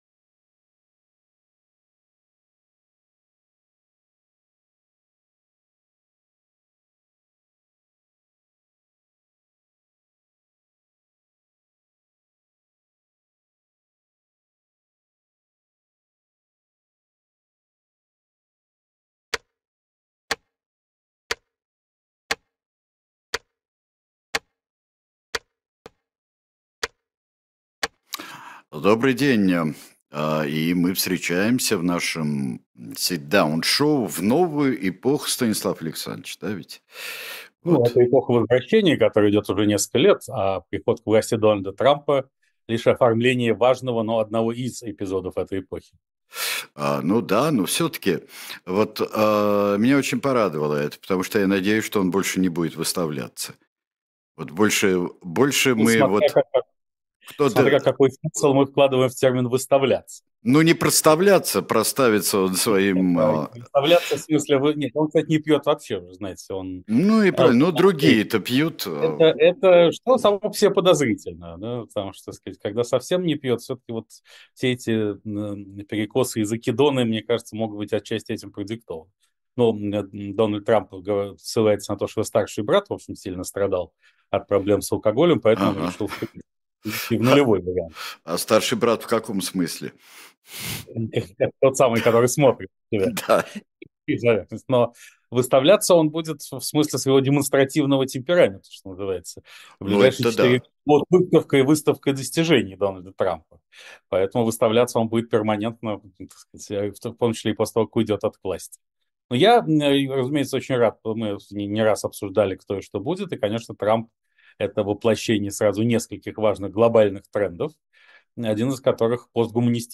Эфир ведёт Сергей Бунтман